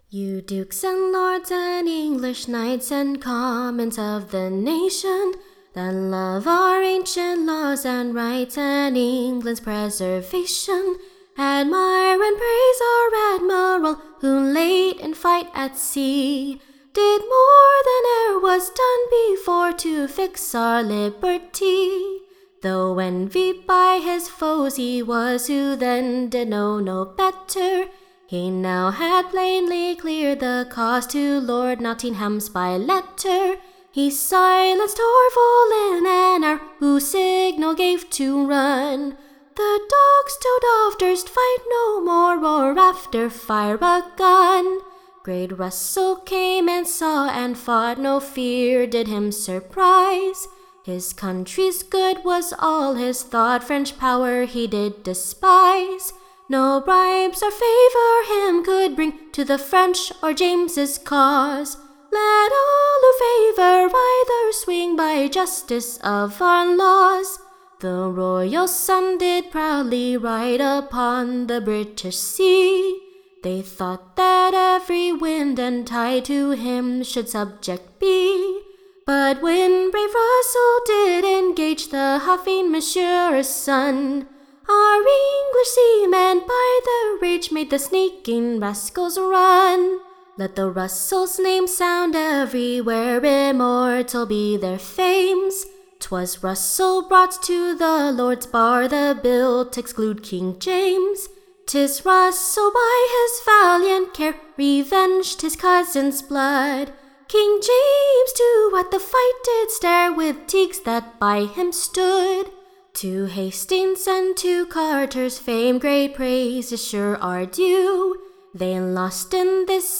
Recording Information Ballad Title A New BALLAD on the Great Victory at SEA, / Obtained over the French by Admiral RUSSEL, May 1692.